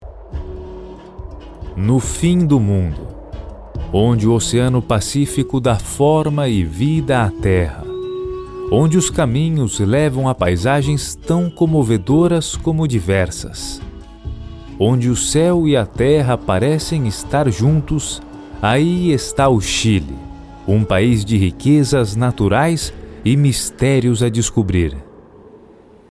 PT BR RA CO 01 Corporate Male Portuguese(Brazilian)